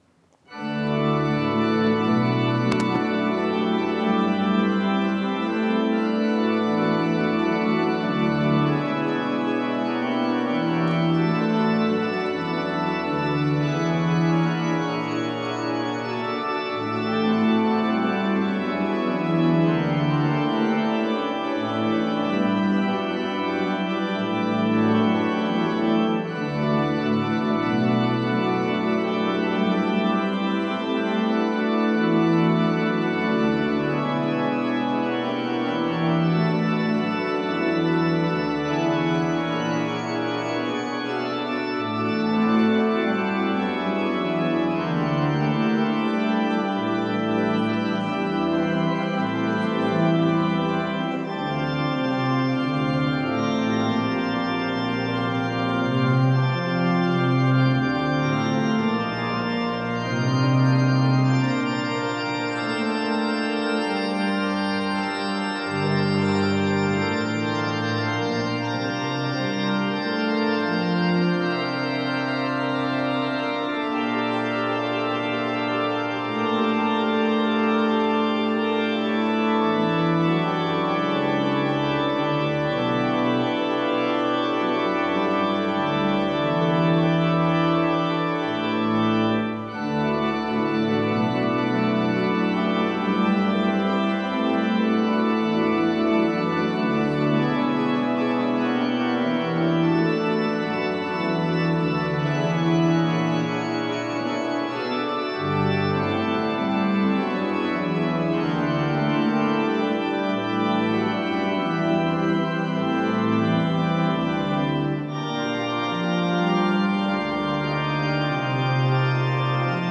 Orgelstück zum Ausgang
Audiomitschnitt unseres Gottesdienstes vom 3. Sonntag nach Trinitatis 2025.